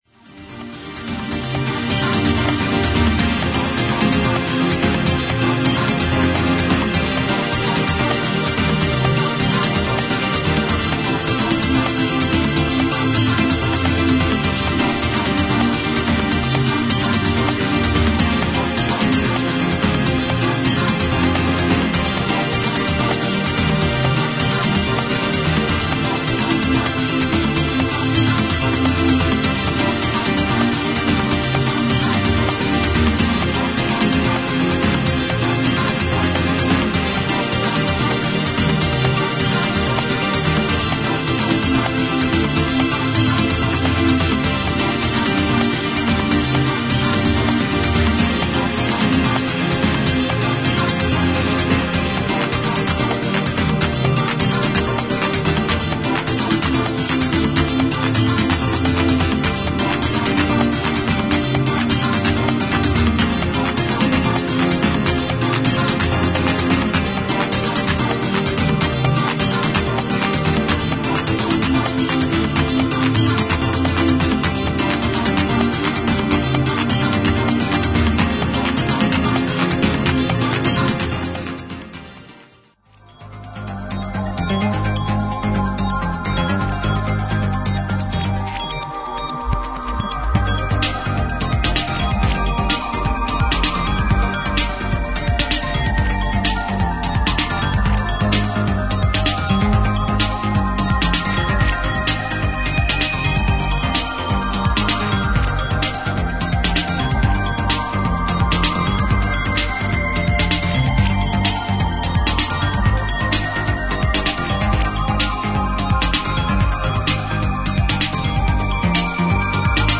初期デトロイト・フィールな4トラックを収録。